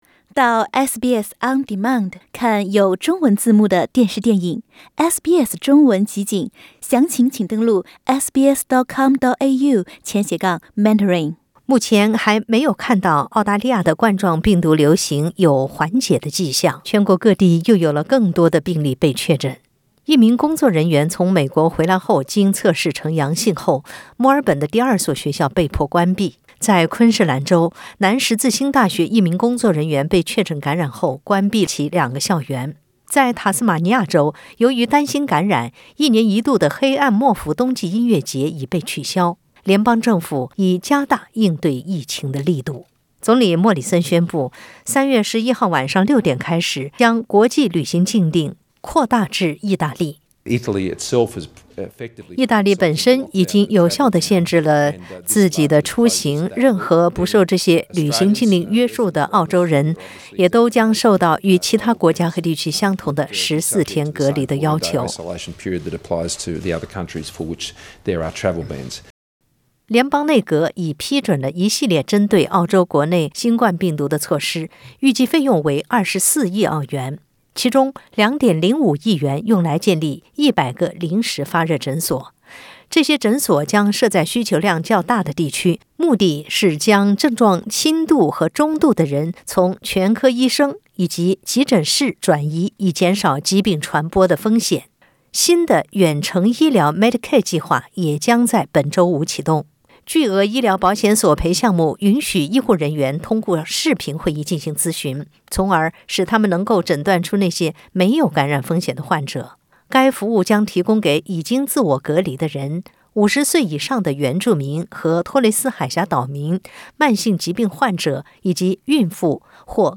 针对正在全球蔓延的新冠病毒，联邦政府推出24亿澳元相应对策。这些政策包括建立新的诊所和远程医疗服务。点击上方图片收听音频报道。